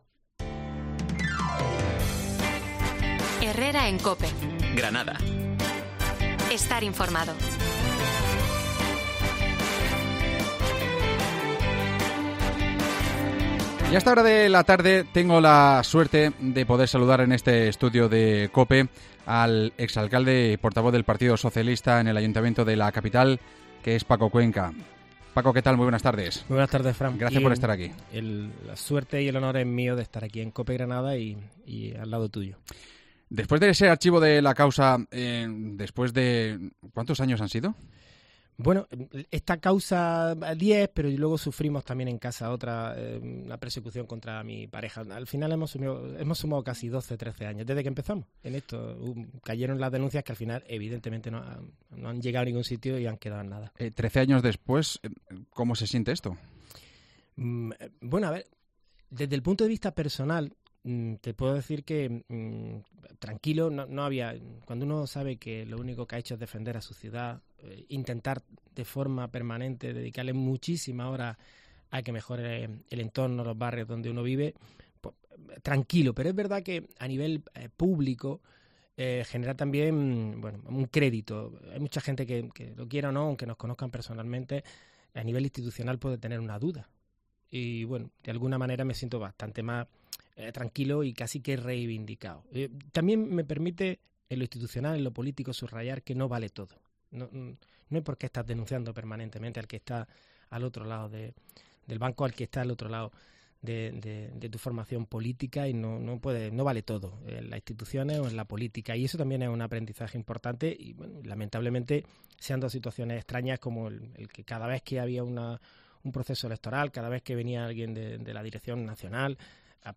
AUDIO: El portavoz del PSOE en el Ayuntamiento ha repasado en COPE la actualida de la ciudad
entrevista